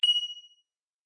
pickup_diamond.ogg